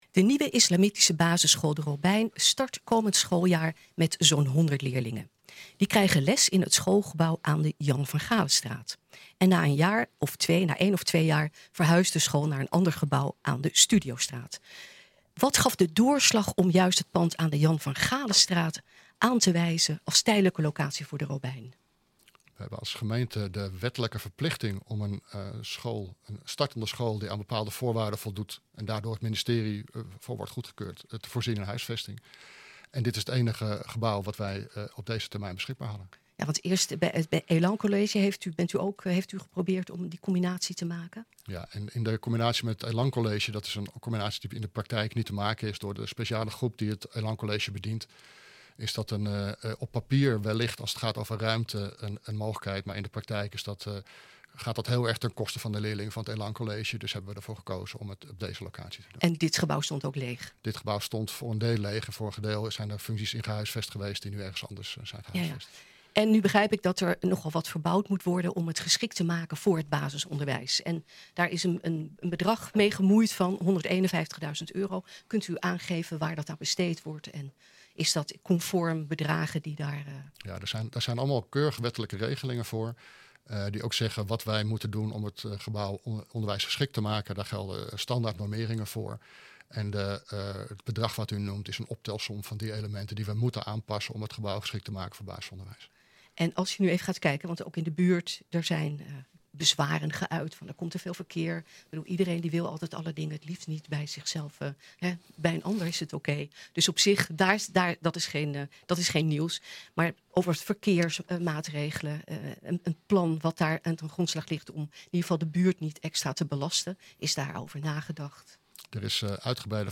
Boom lichtte zijn standpunt ook toe in een radio-interview bij NH Gooi.
Wethouder Boom praat ons hierover bij.